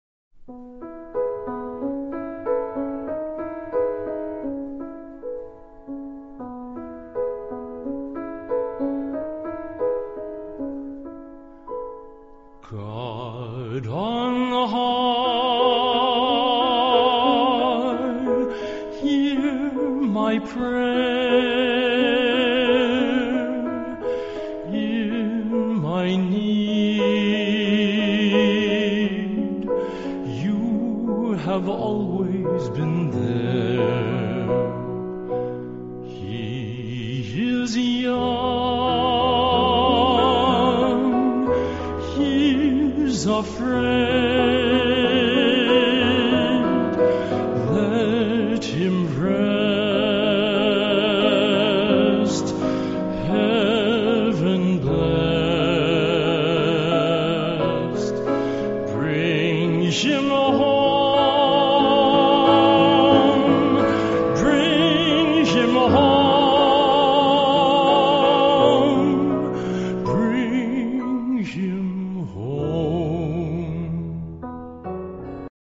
a gentle voice
baritone
light, velvety, and sweet without ever being cloying